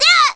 File File history File usage JPokeFushigisouDamageFly01.wav  (WAV audio file, length 0.4 s, 288 kbps overall) Unused Ivysaur audio This file is an audio rip from a(n) Wii game.